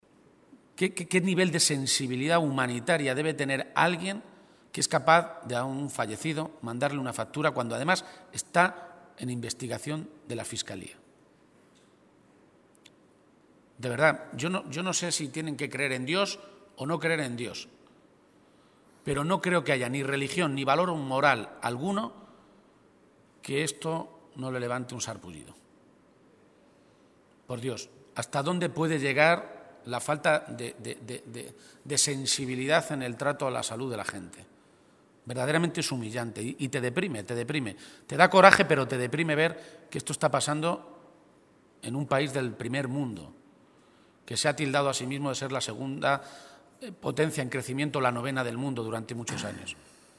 García-Page se pronunciaba de esta manera, esta mañana, en Toledo, a preguntas de los medios de comunicación, al tiempo que exigía “una reacción inmediata al Gobierno de Cospedal.
Cortes de audio de la rueda de prensa